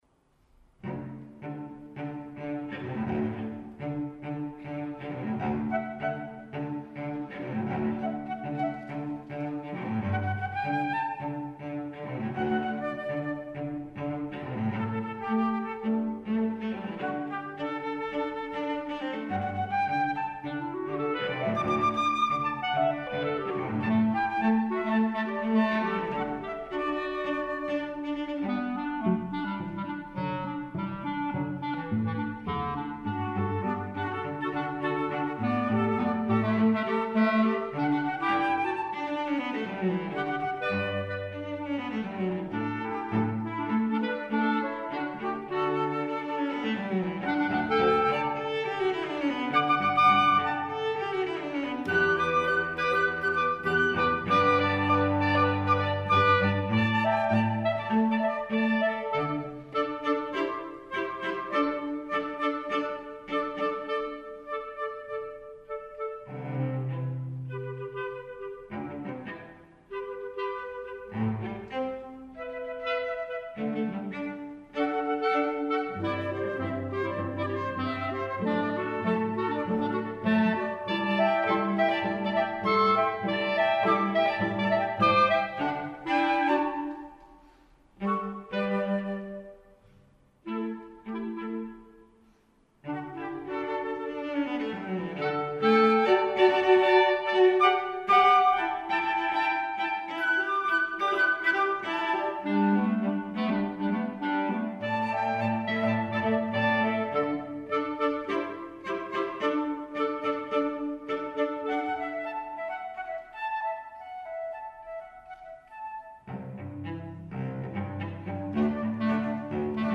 for Flute, Clarinet, and Cello (2016)